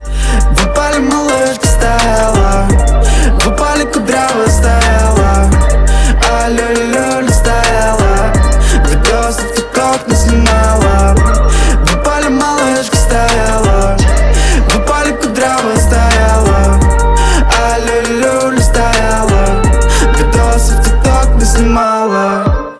• Качество: 128, Stereo
громкие
зажигательные
веселые